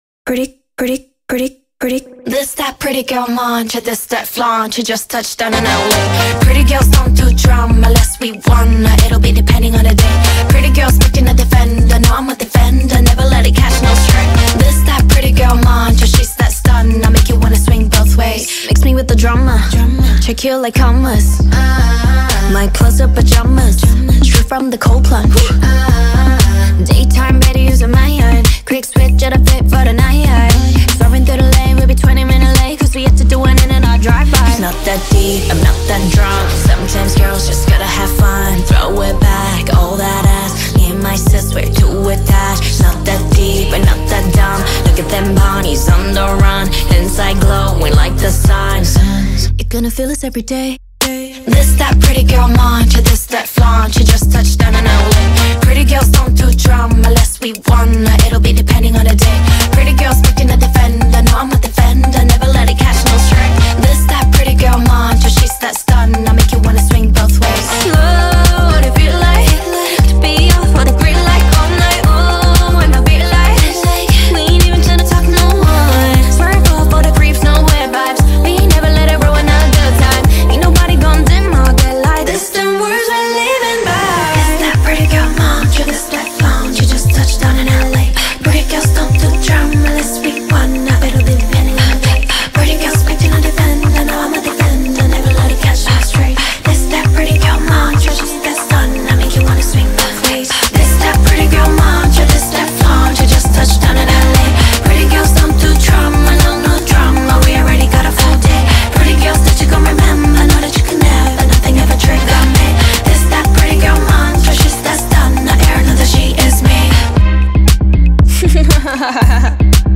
ژانر: K-pop